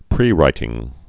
(prērītĭng)